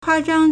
夸张 (誇張) kuāzhāng
kua1zhang1.mp3